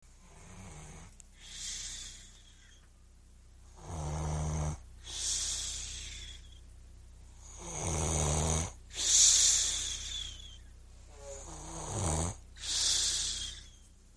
Download Sleep Sounds sound effect for free.
Sleep Sounds